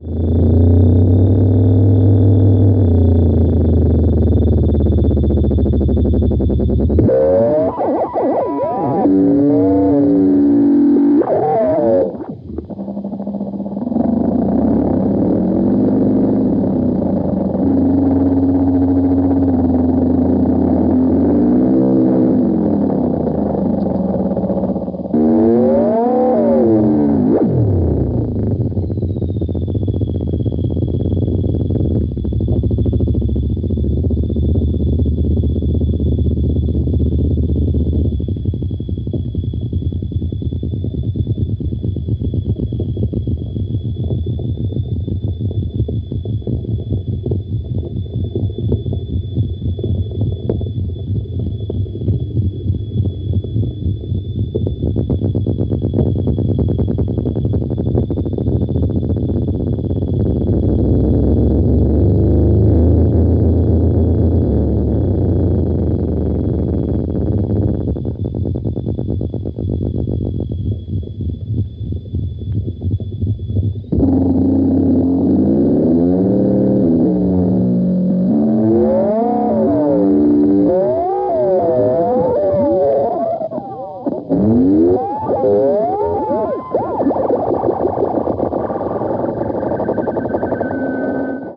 tape loops in contrapuntal collision.